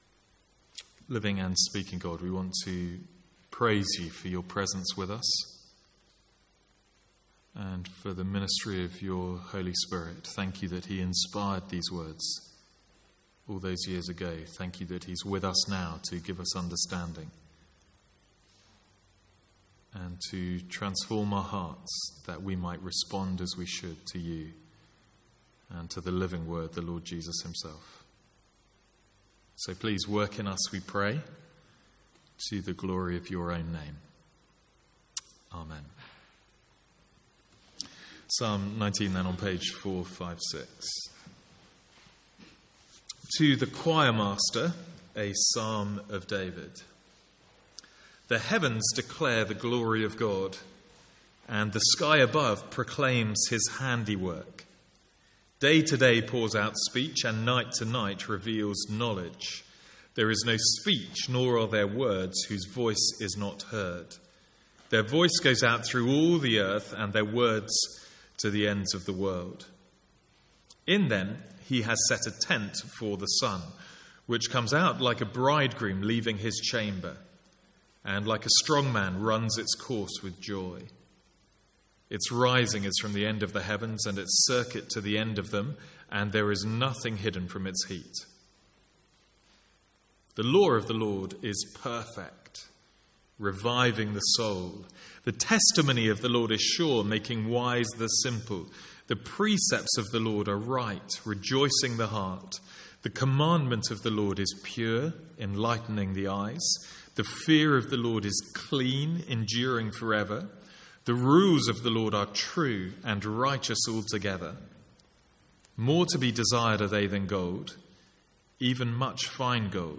From the Sunday evening series in the Psalms.